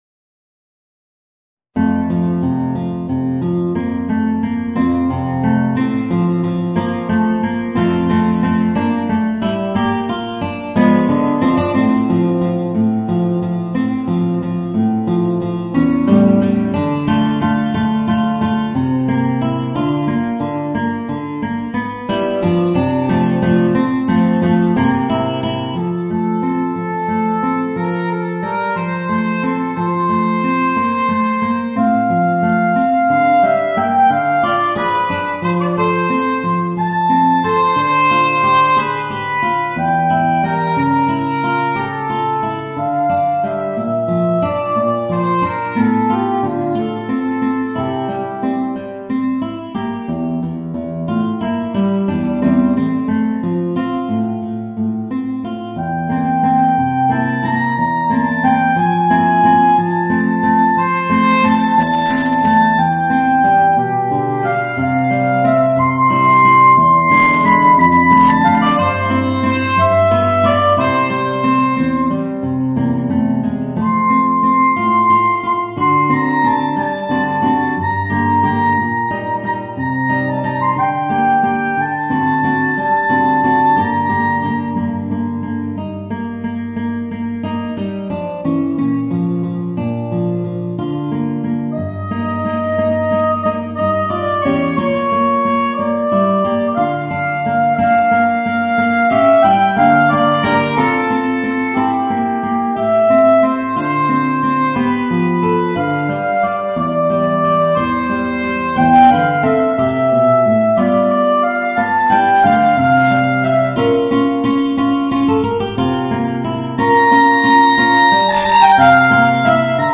Voicing: Soprano Saxophone and Guitar